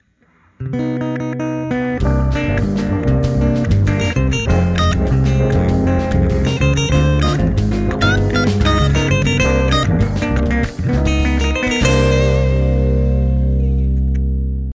Kategori Klassisk